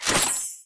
ui_interface_13.wav